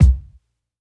Kicks
Medicated Kick 9.wav